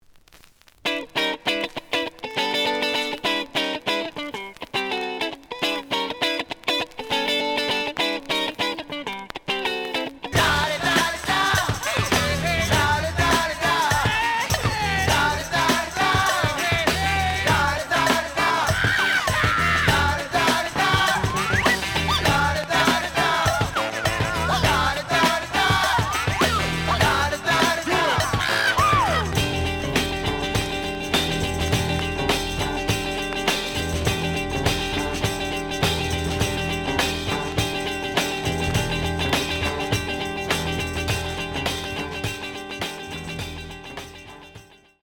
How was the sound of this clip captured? The audio sample is recorded from the actual item. ●Format: 7 inch Some click noise on B side due to scratches.